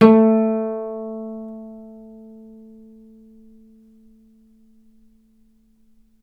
healing-soundscapes/Sound Banks/HSS_OP_Pack/Strings/cello/pizz/vc_pz-A3-ff.AIF at ae2f2fe41e2fc4dd57af0702df0fa403f34382e7
vc_pz-A3-ff.AIF